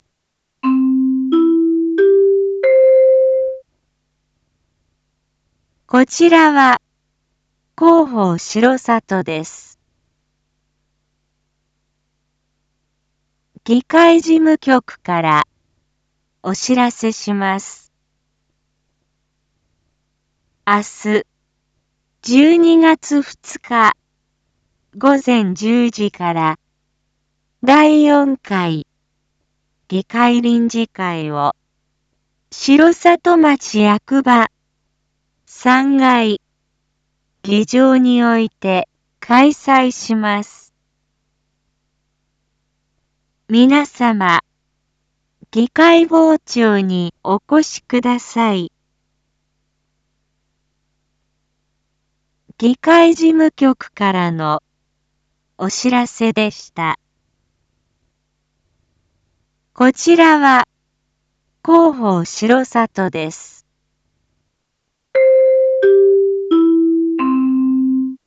Back Home 一般放送情報 音声放送 再生 一般放送情報 登録日時：2025-12-01 19:01:17 タイトル：R7.12.2 第４回議会定例会① インフォメーション：こちらは広報しろさとです。